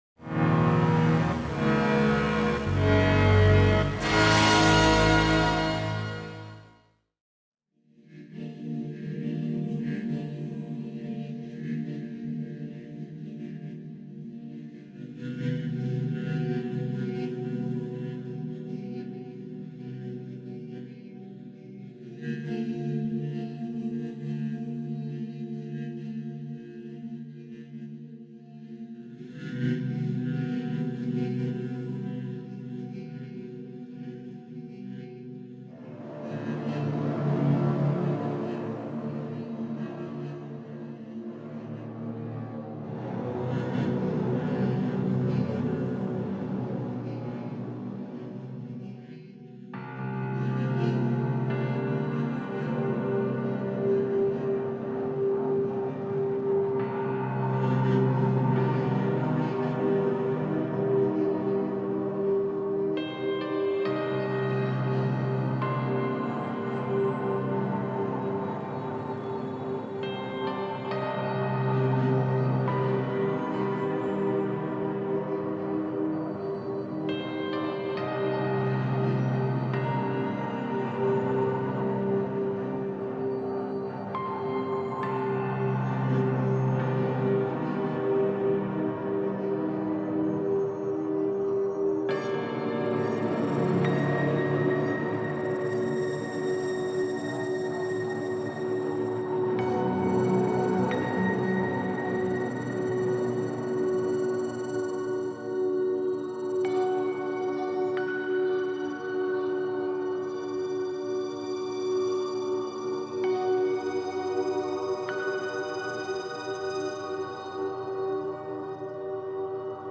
LAwsKk4cn3N_cathartic-slums-sci-fi-ambience-piece.mp3